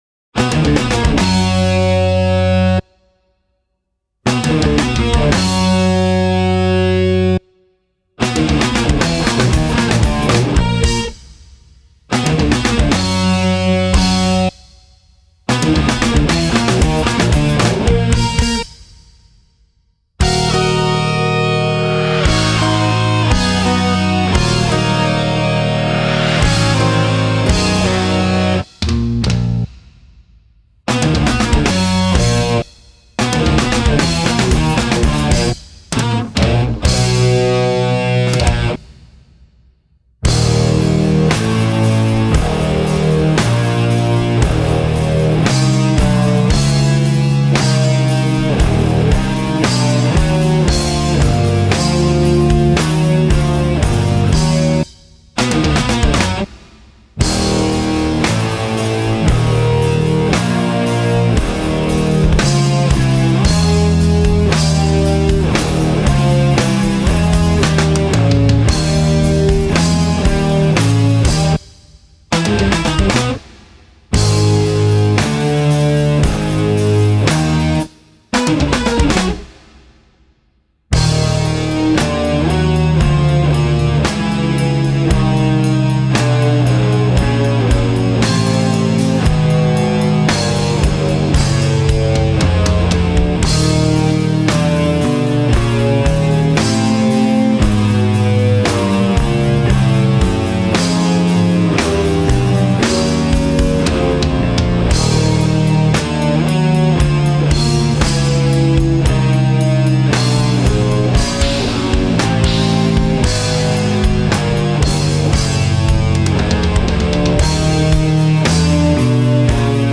Будет слушать разные исполнения одного и того же произведения.